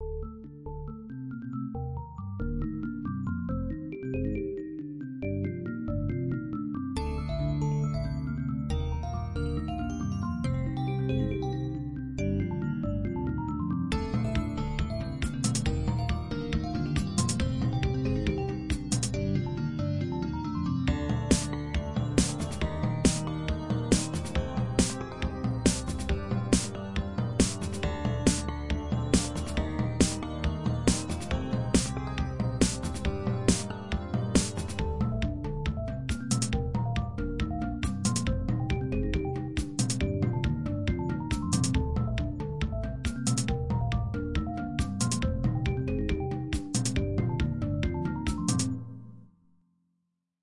标签： 收音机 语音 口语 语音 也就是说
声道立体声